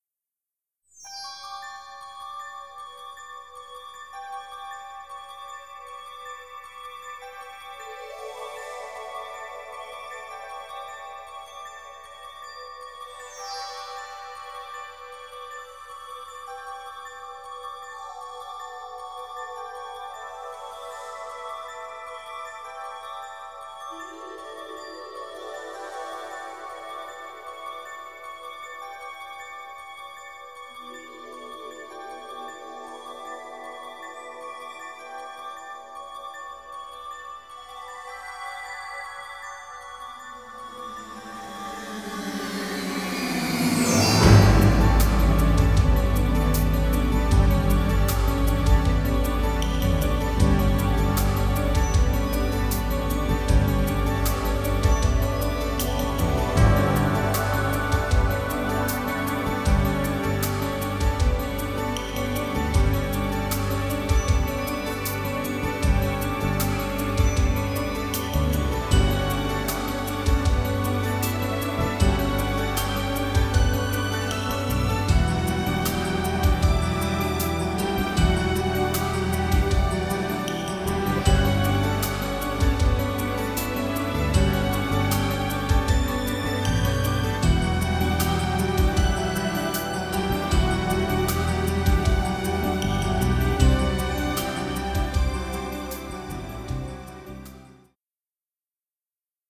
dark thriller score
original stereo session mixes